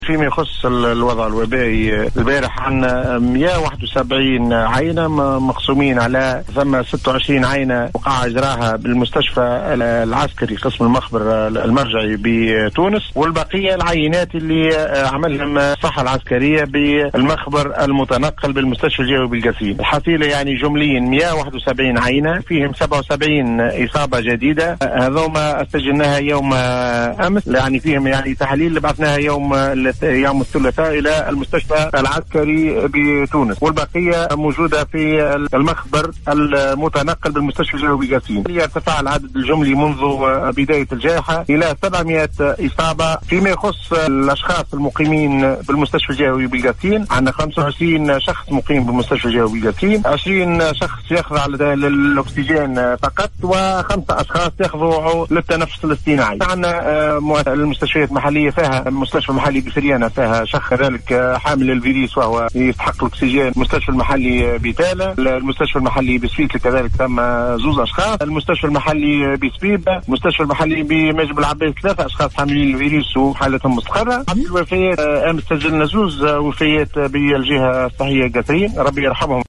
المدير الجهوي للصحة بالقصرين الدكتور عبد الغني الشعباني